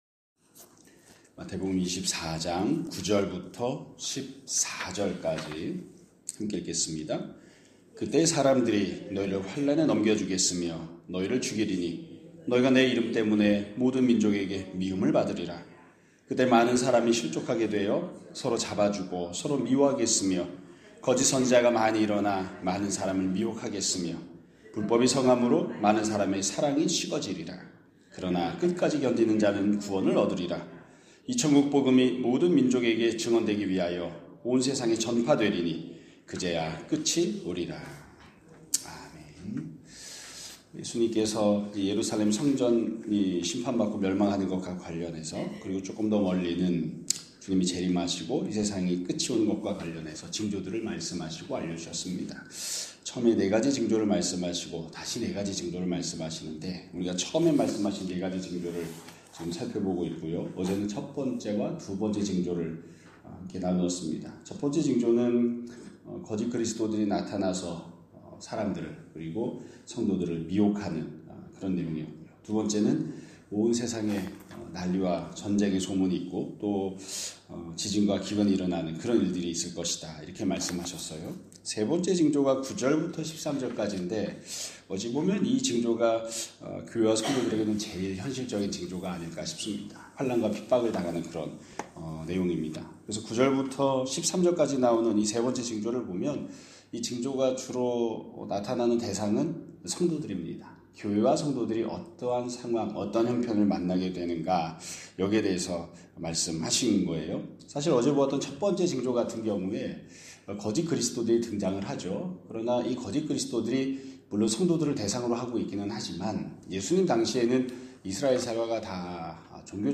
2026년 3월 10일 (화요일) <아침예배> 설교입니다.